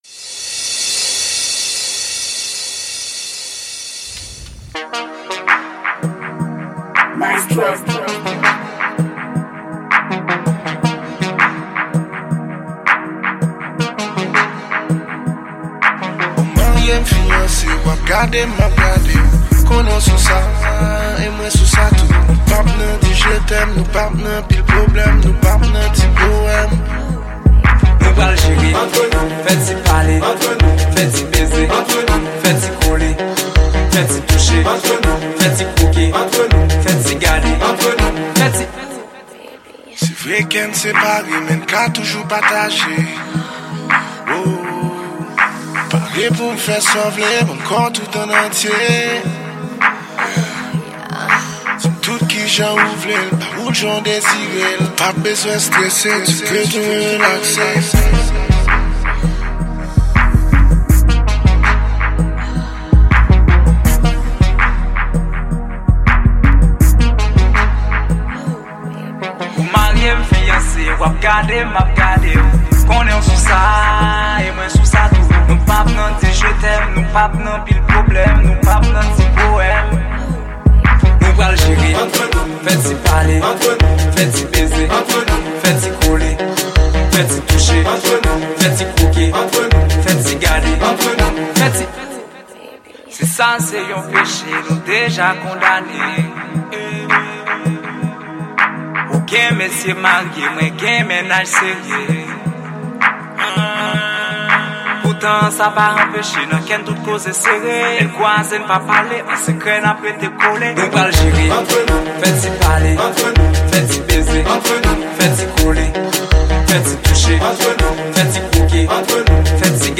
Genre: Mixtape Konpa.
MIXTAPE KOMPA